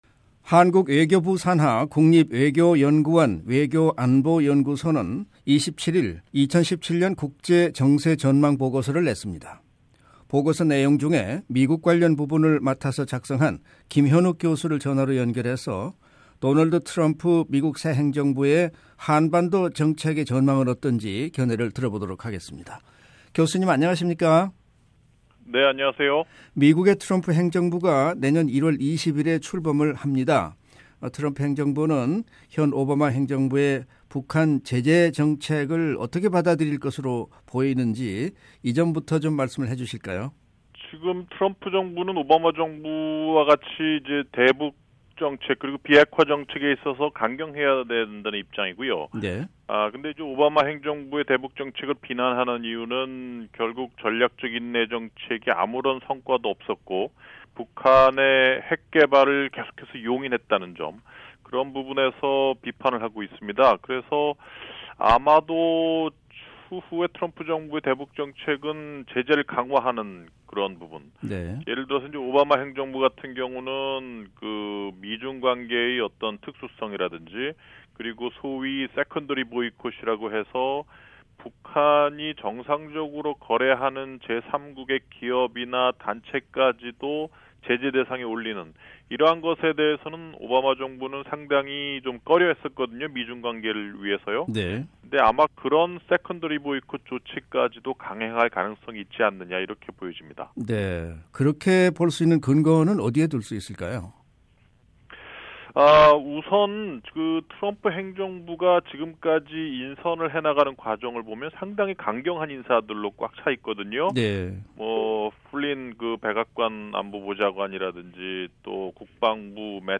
[인터뷰 오디오: